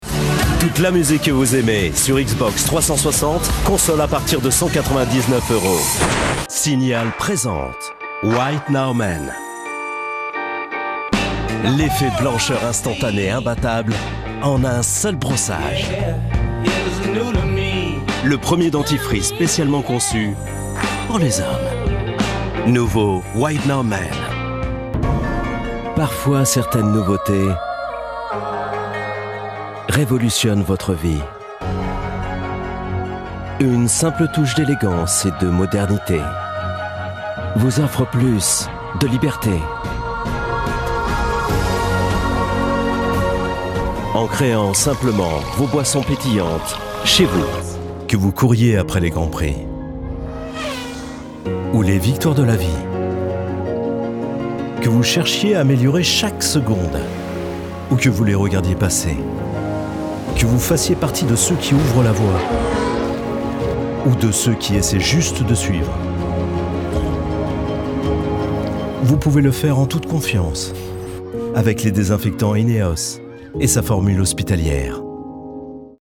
Male
Assured, Character, Confident, Conversational, Cool, Corporate, Deep, Engaging, Friendly, Gravitas, Natural, Reassuring, Sarcastic, Smooth, Soft, Versatile, Warm
commercial_English.mp3
Microphone: Neumann TLM103